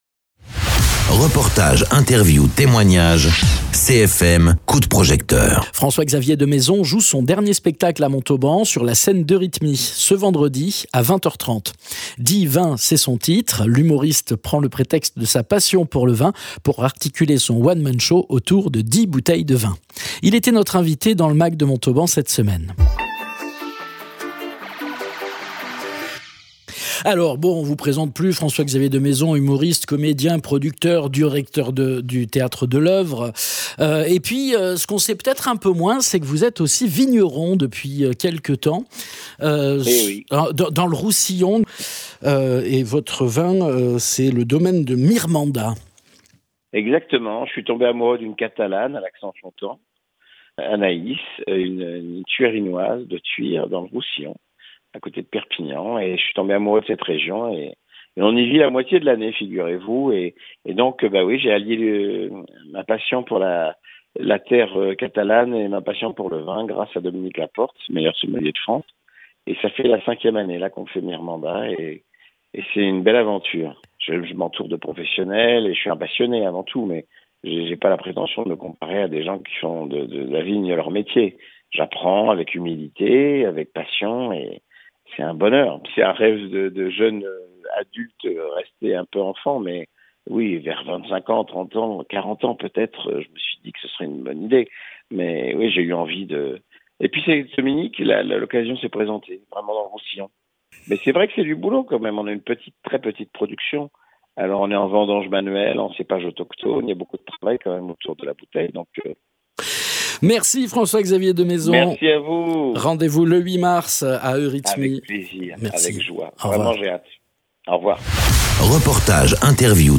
Interviews
Invité(s) : François Xavier Demaison